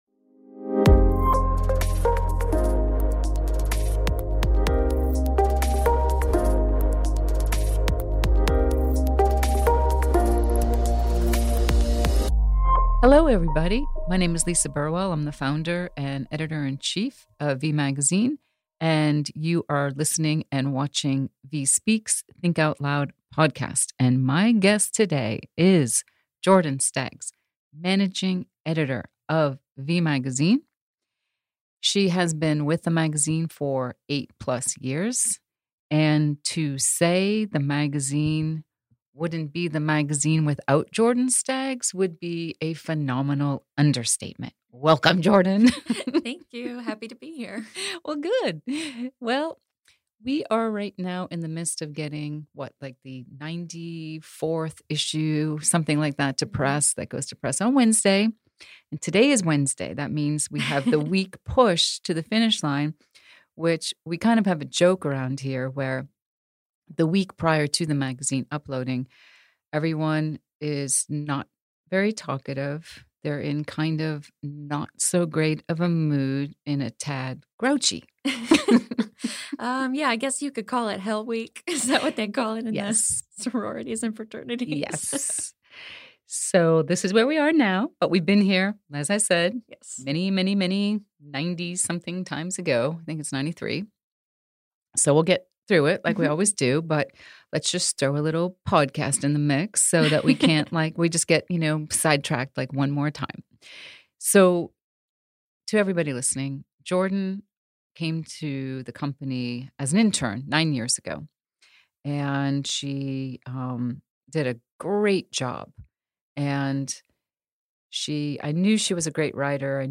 4. “VIE Adventures, Part II” – A Conversation